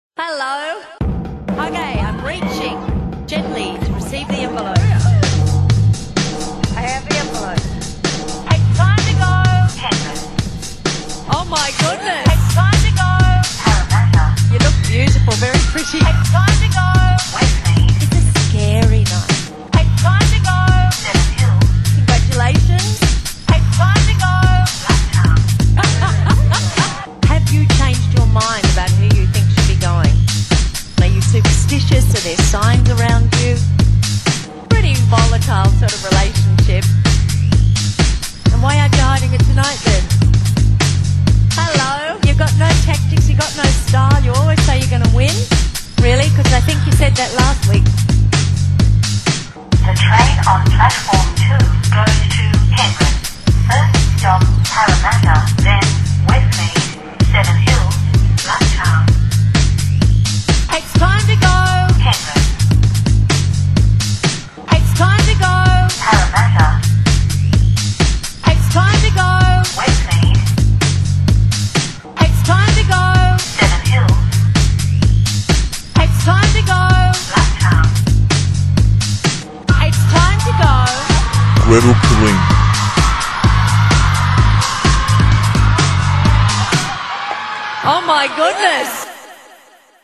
The best thing about Sydney's transport system is that the person who announces the trains is the same person who hosts Big Brother.